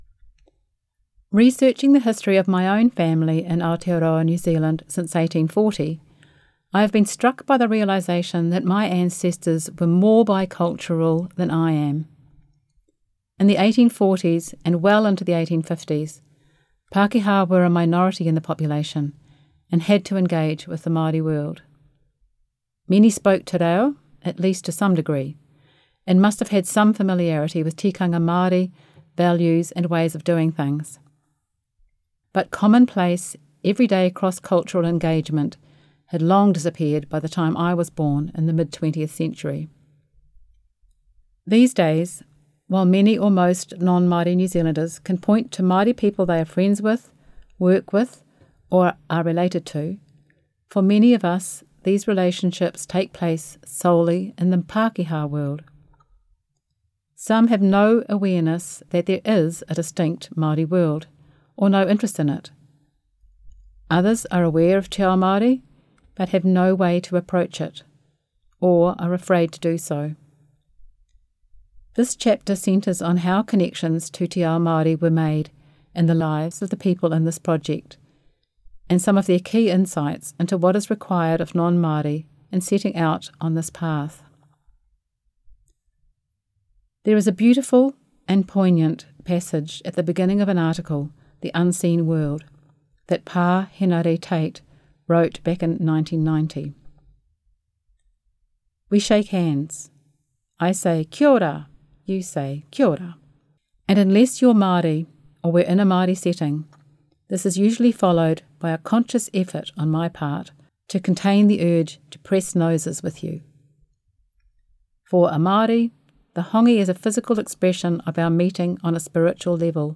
Format: Audiobook